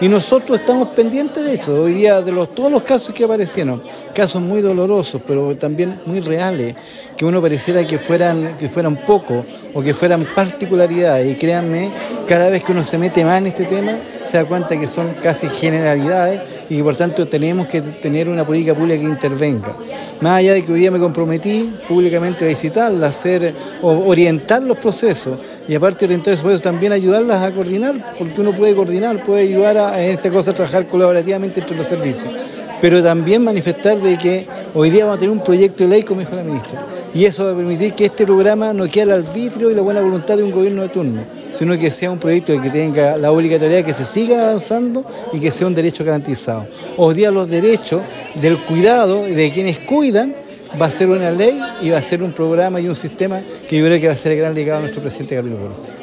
En tanto el Seremi de Desarrollo Social y Familia en Los Lagos, Enzo Jaramillo agradeció la convocatoria que tuvo la actividad, además de manifestar que dadas las condiciones geográficas de la región muchas de las prestaciones técnicas se enfocan en las realidades territoriales.